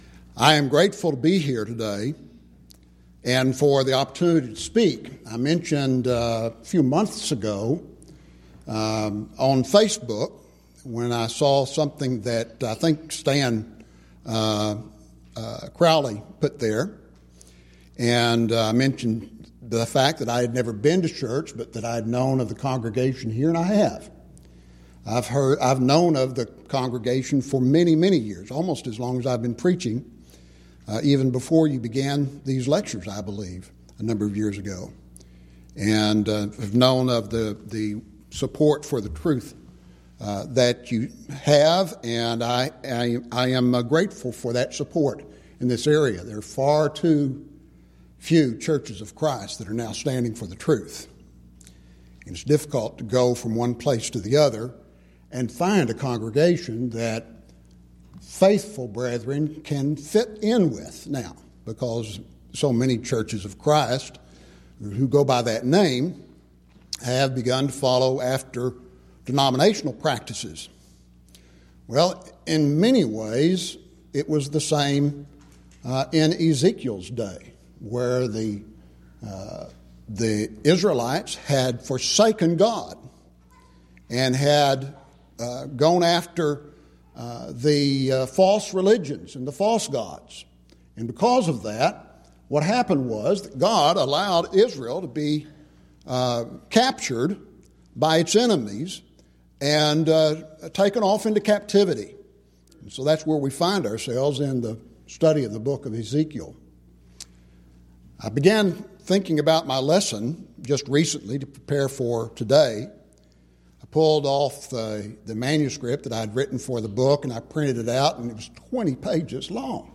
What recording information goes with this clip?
Series: Schertz Lectureship Event: 10th Annual Schertz Lectures